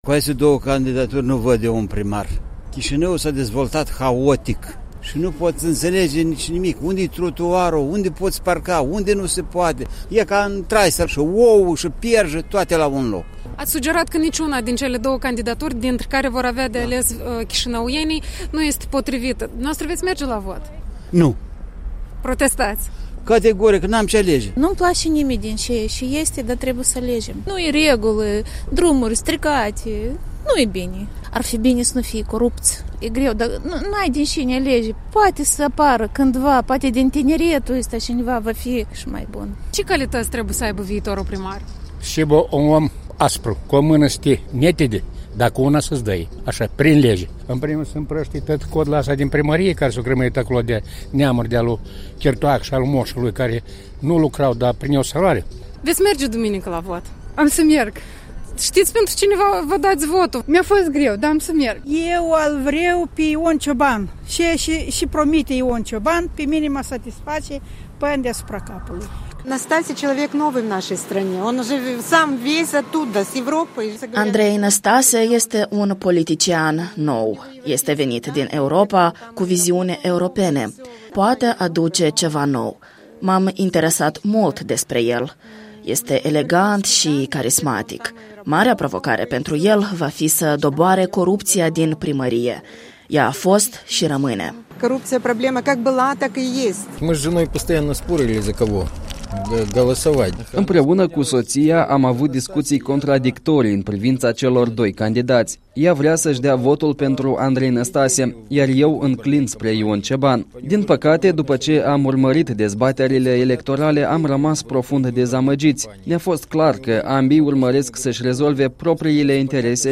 Vox pe străzile din Chișinău, înainte de al doilea tur de scrutin al alegerilor locale